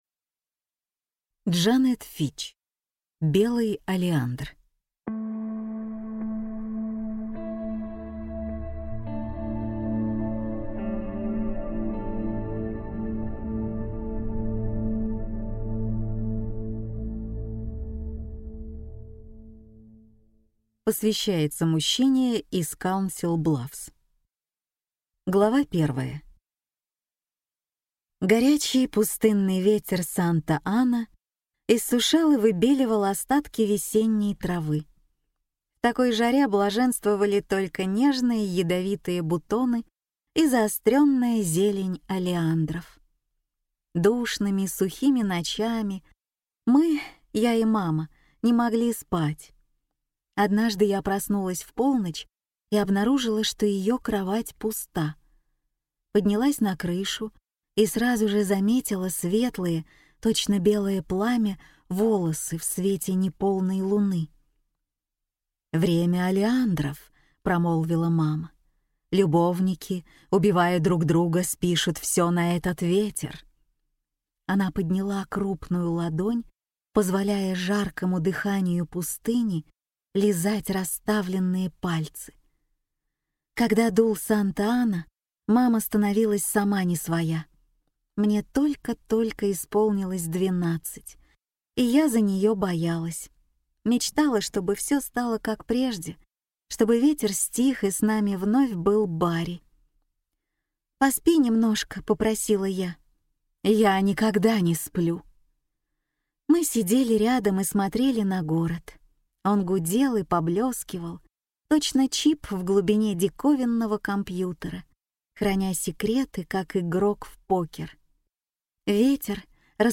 Аудиокнига Белый олеандр | Библиотека аудиокниг